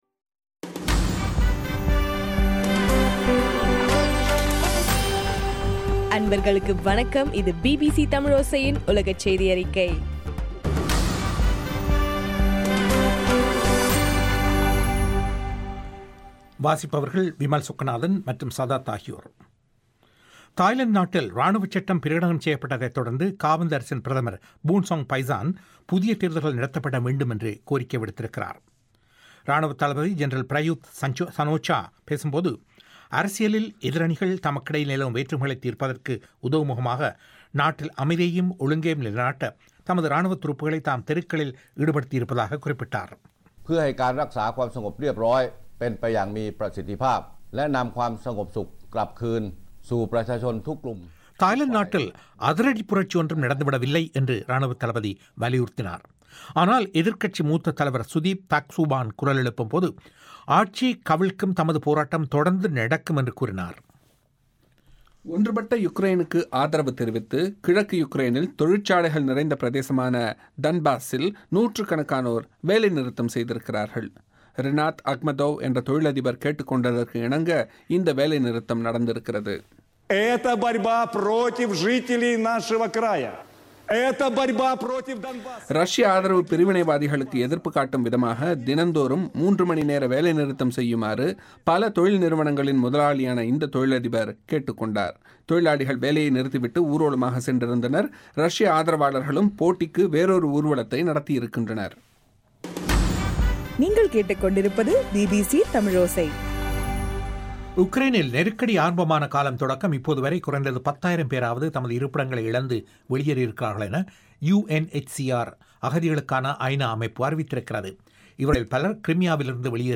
மே 20 பிபிசியின் உலகச் செய்திகள்